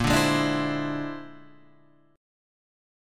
A#9b5 chord {x 1 0 1 1 0} chord